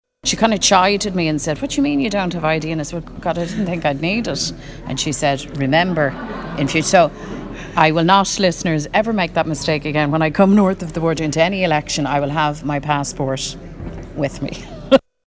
Mary Lou says she won’t make the same mistake again: